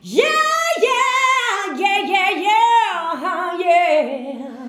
YEAHYEAAH.wav